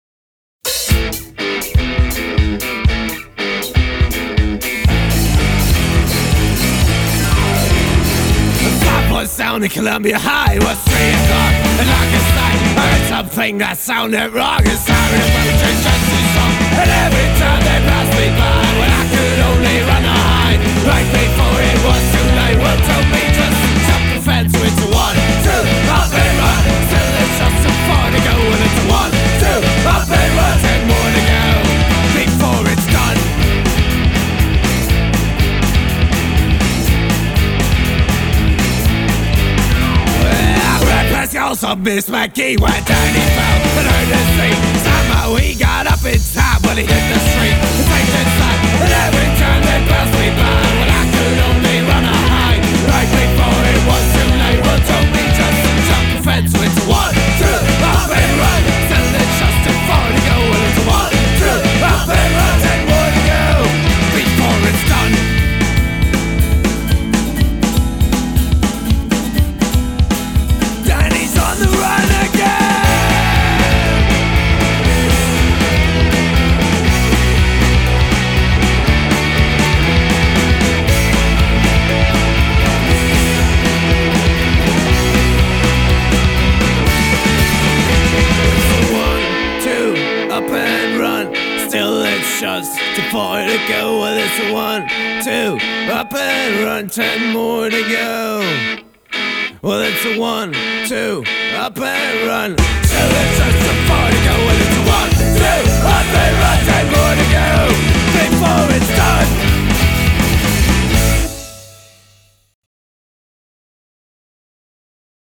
European Psychobilly at its best.